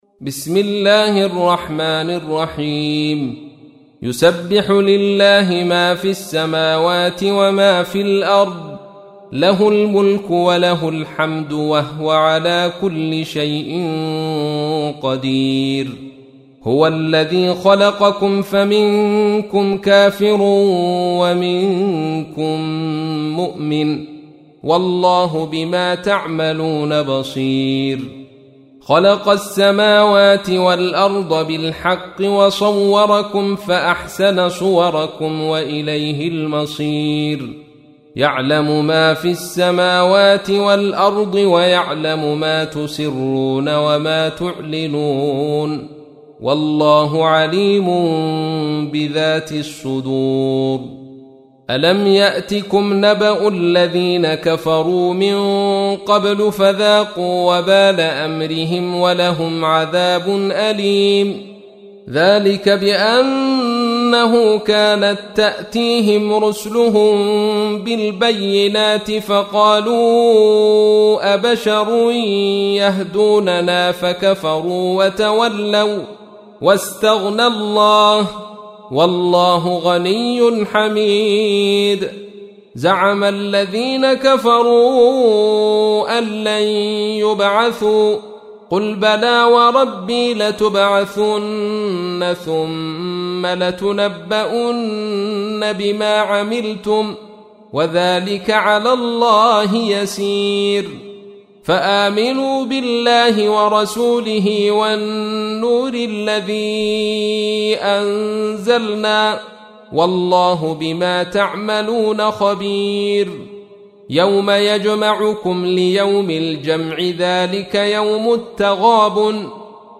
تحميل : 64. سورة التغابن / القارئ عبد الرشيد صوفي / القرآن الكريم / موقع يا حسين